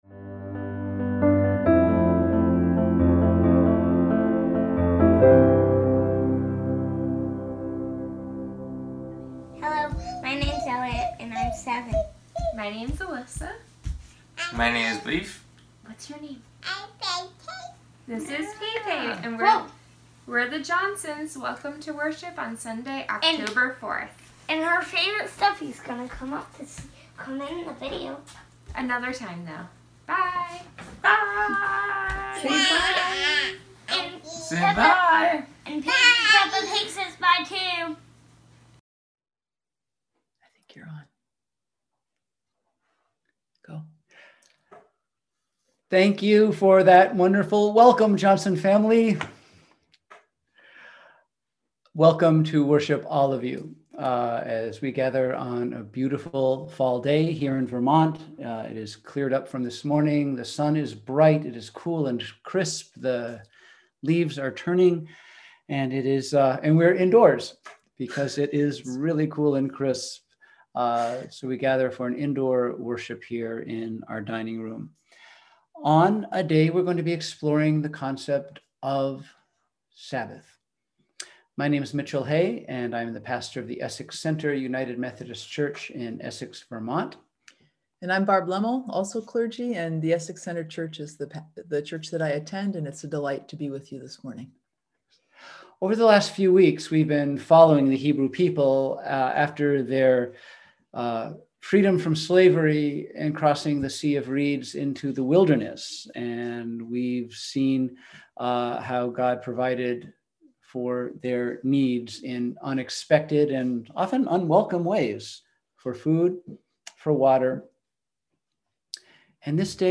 We held virtual worship on Sunday, October 4, 2020 at 10am.